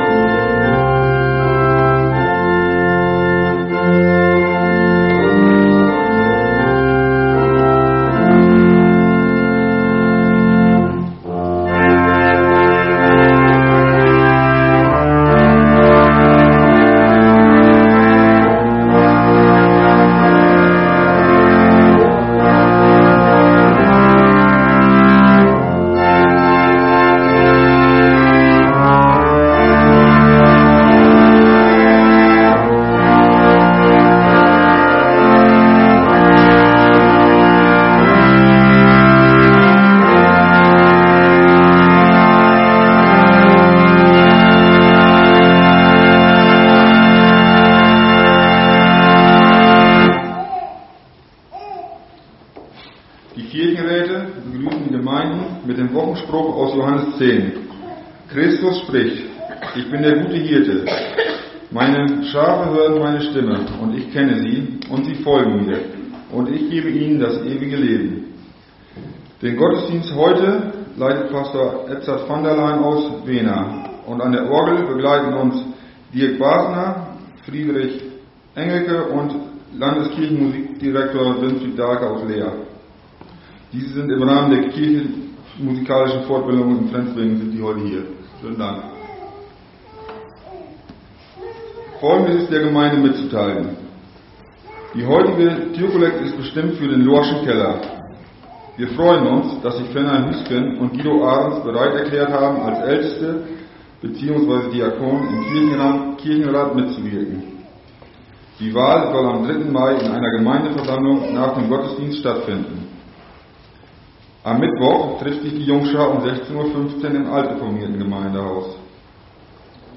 Wir laden ein, folgende Lieder aus dem Evangelischen Gesangbuch und dem Liederheft mitzusingen: Lied 177, 2, Lied 609, 1 – 3, Lied 288, 1 – 4 + 6 + 7, Lied 612, 1 – 3, Lied 209, 1 – 4, Psalm 89, 1 und Psalm 89, 5 + 6